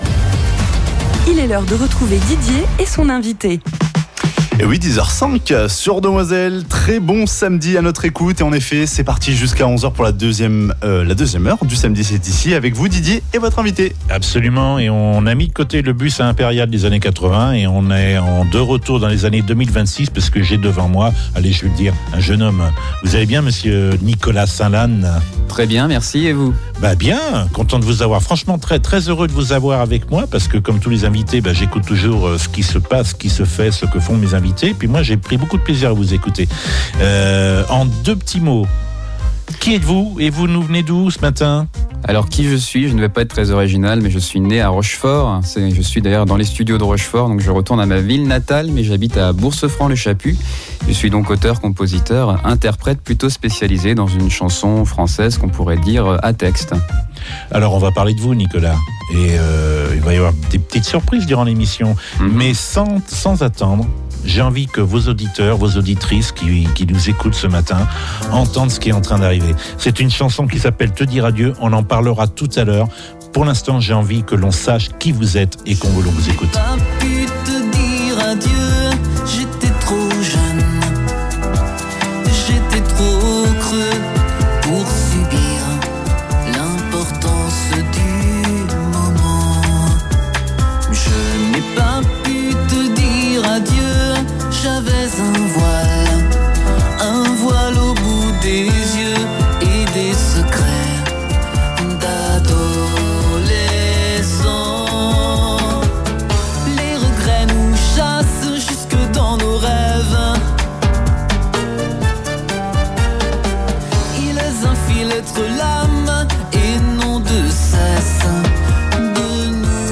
Rencontre avec ce passionné de musique et des mots avec en bonus deux lives avec "Au delà des rêves" et "Les cerisiers en fleurs".